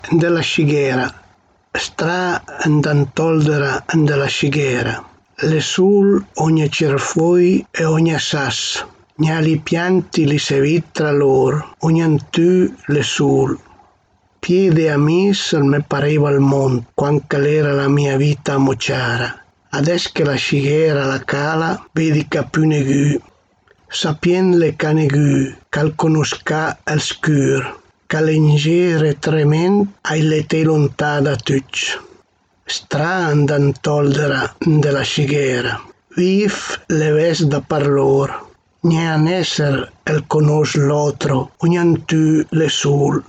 Ndèla scighèra | Dialetto di Albosaggia